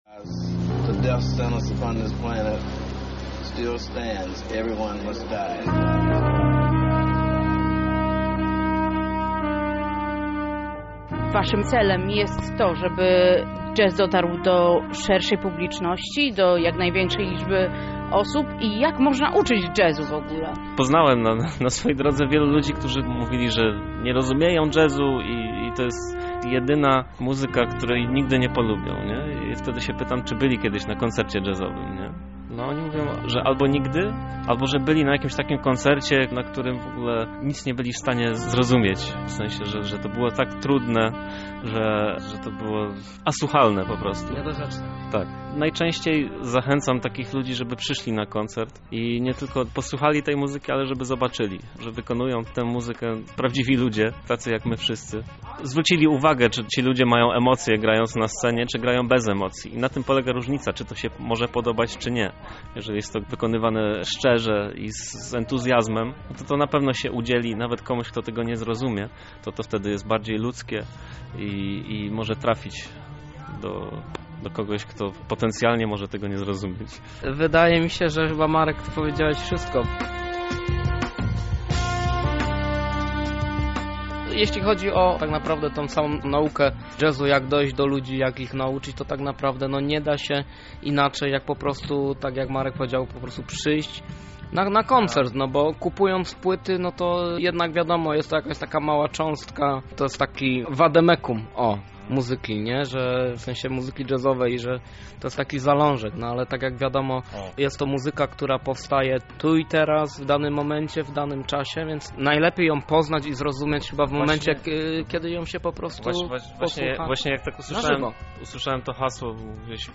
Danie główne: Wywiad z zespołem EABS
EABS – wywiad
EABS-wywiad.mp3